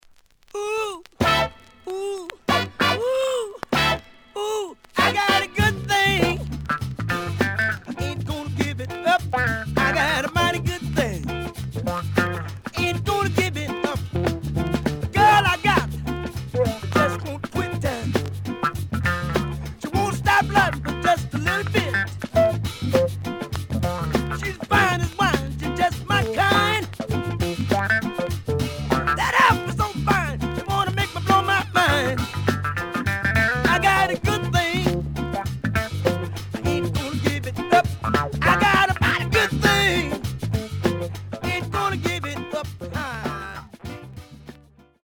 The listen sample is recorded from the actual item.
●Genre: Funk, 70's Funk